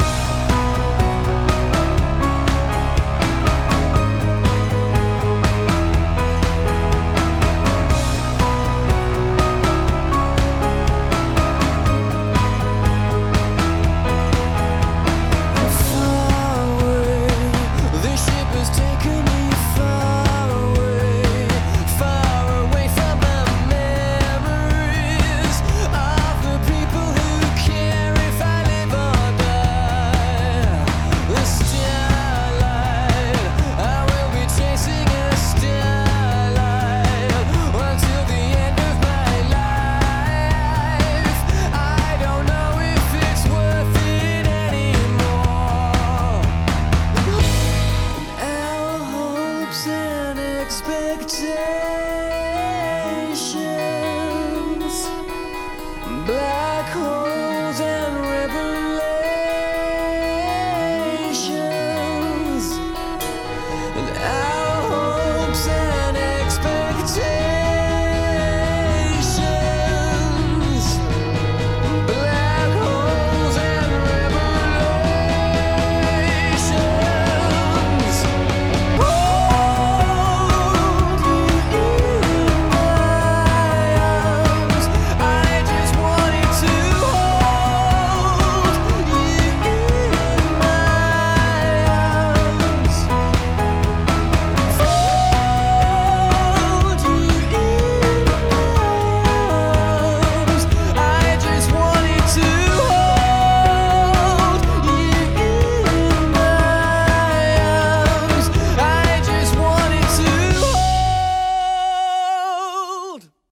BPM120-124
Audio QualityMusic Cut